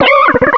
sovereignx/sound/direct_sound_samples/cries/simipour.aif at master